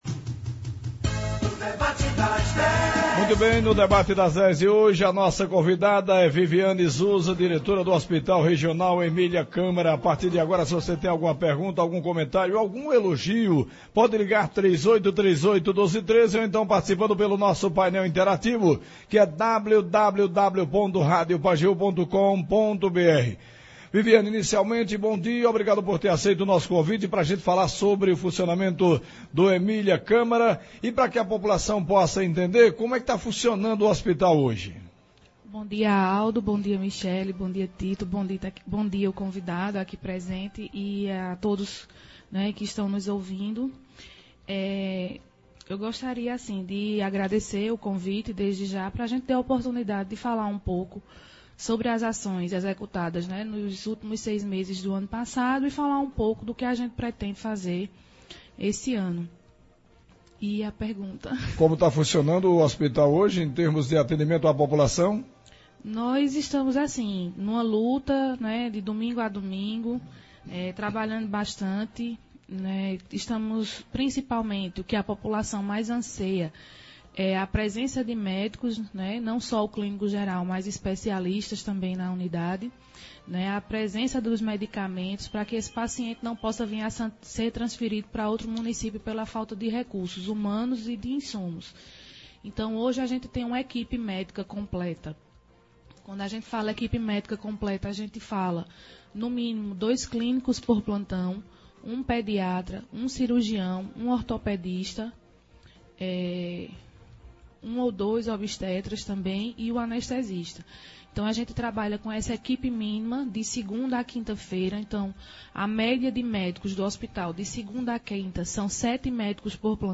Acompanhe abaixo alguns trechos da entrevista: